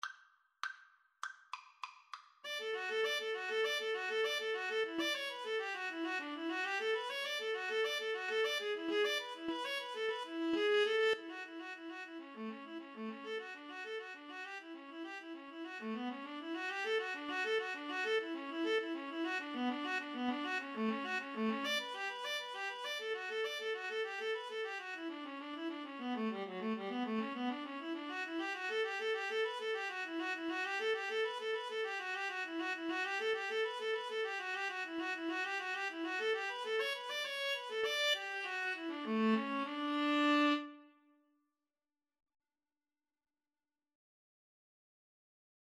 Free Sheet music for Viola-Cello Duet
D major (Sounding Pitch) (View more D major Music for Viola-Cello Duet )
Allegro =200 (View more music marked Allegro)
Classical (View more Classical Viola-Cello Duet Music)